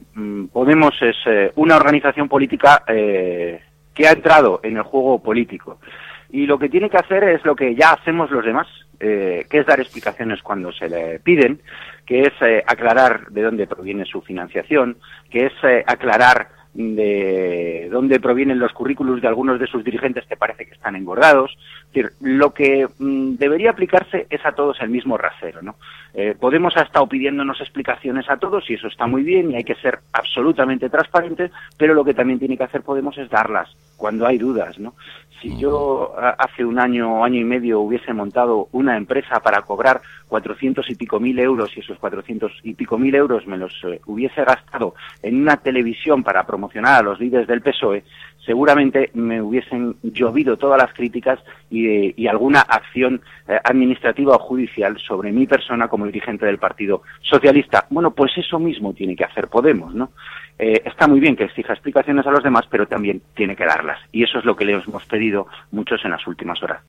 Fragmento de la entrevista de Antonio Hernando en Onda Cero el 29/1/2015 en la que pide a los dirigentes de Podemos que ofrezcan explicaciones sobre su financiación y sobre los CV "inflados"